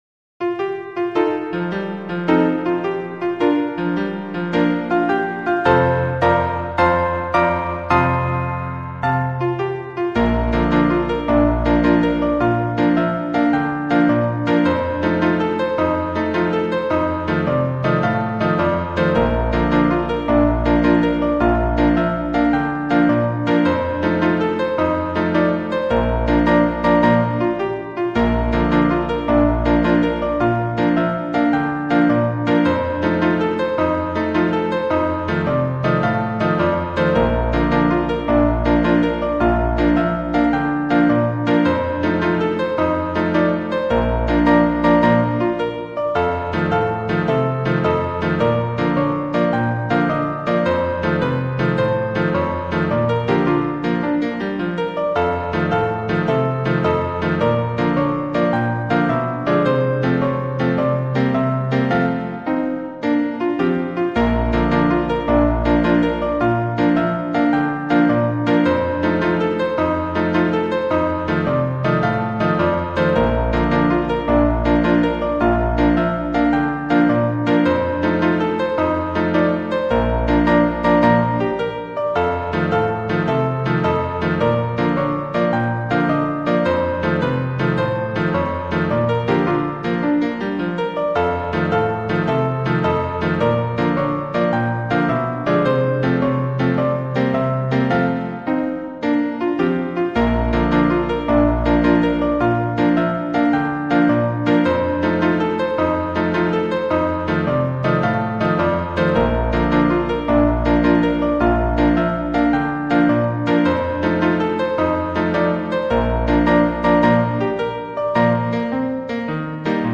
We selected instruments that sounded like upright Victorian pianos; in many cases, the lyrics line is played back as a honky-tonk piano, while the supporting bass and treble lines were kept to more sedate parlor pianos. The reverb is what we imagine these tunes would have sounded like had then been played in the Hotel Florence (with its Minton tile lobby) in 1885.